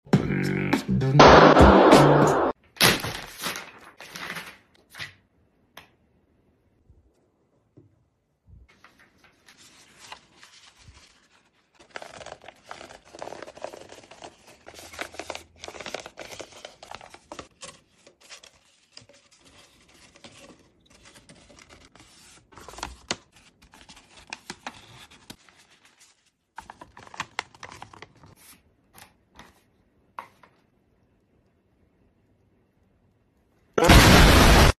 Extreme paper popper sound effects free download